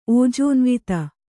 ♪ ōjōnvita